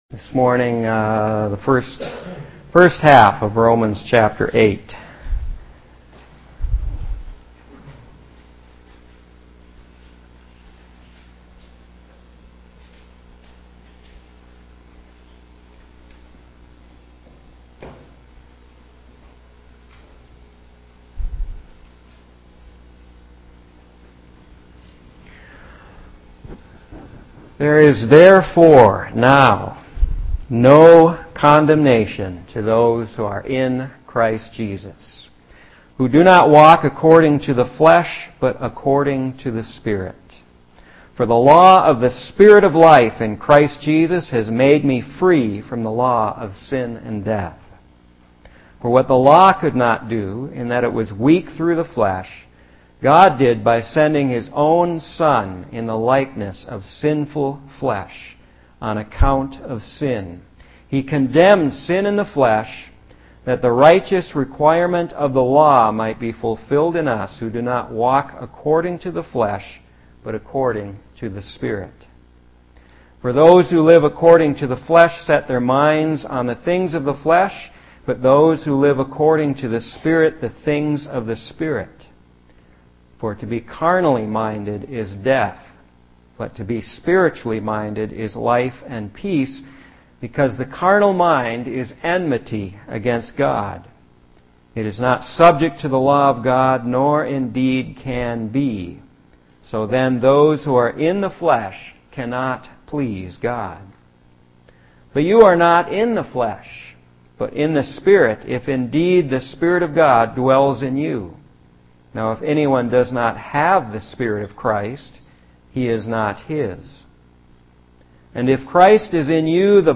preaching from Romans 8:1-18, the incredible good news that there is no condemnation whatsoever for those in Christ, and He gives RESURRECTION LIFE even to our mortal bodies!